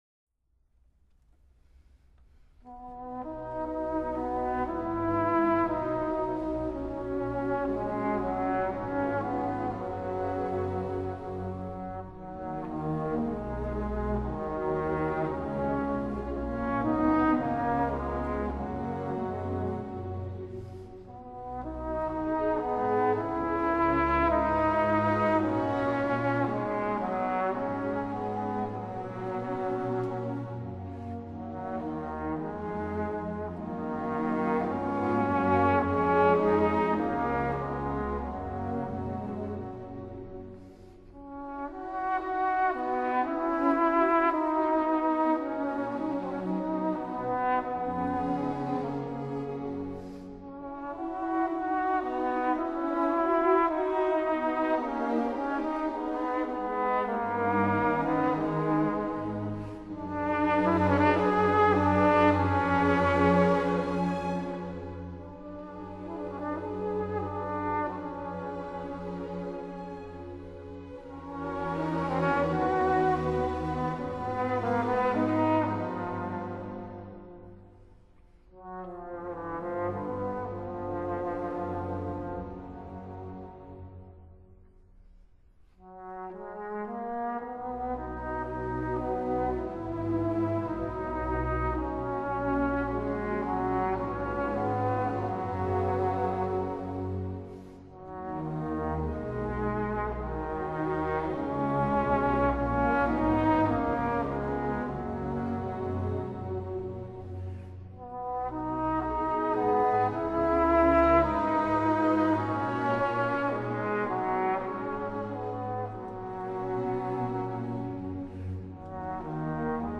Classical
Trumpet & Flugelhorn